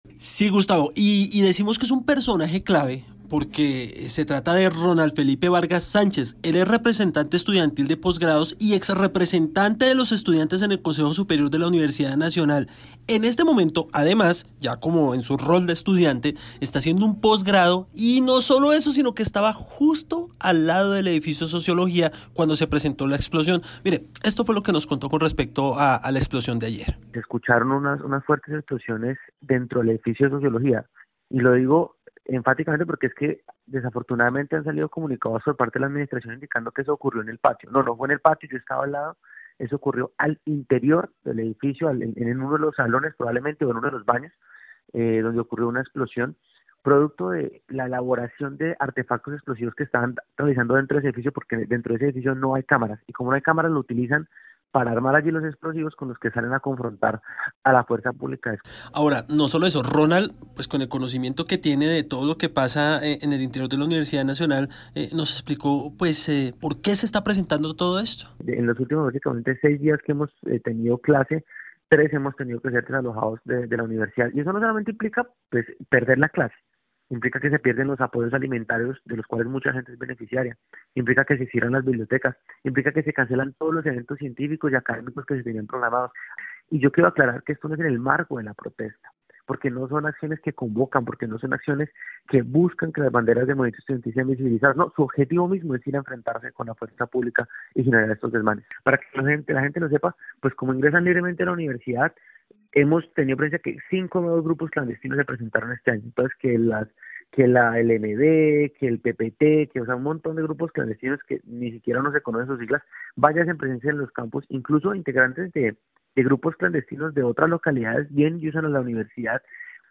En Caracol Radio hablamos con un testigo, del momento en el que se presentó la explosión en el edificio de Sociología del campus y que dio detalles de lo que pasa en la Universidad Nacional.